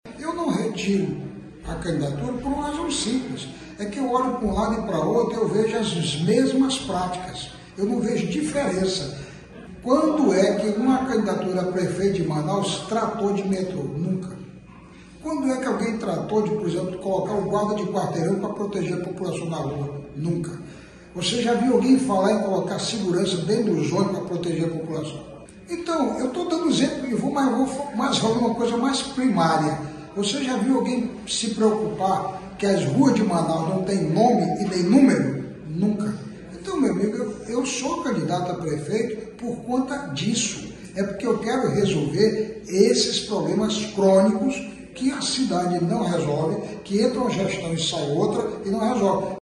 Em declaração a BandNews Difusora FM, o pré-candidato a prefeito Eron Bezerra, esclareceu que, com a decisão, o grupo político passa a ter dois pré-candidatos.